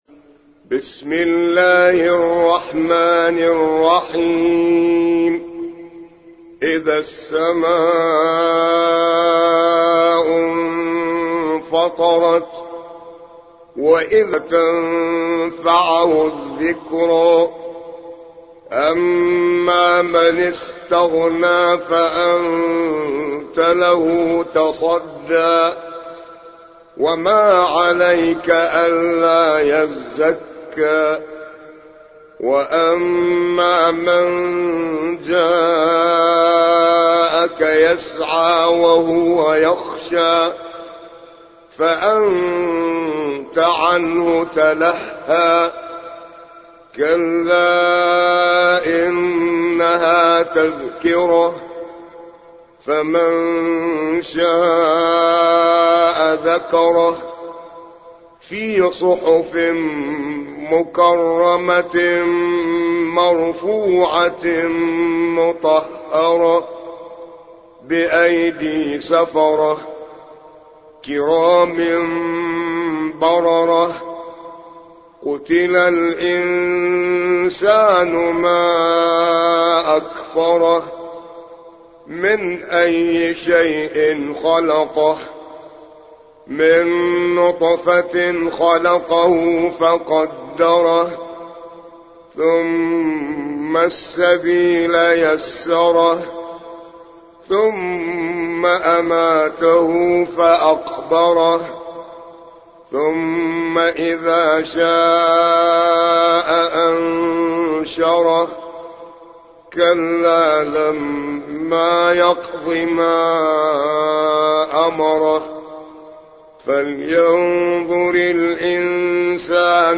80. سورة عبس / القارئ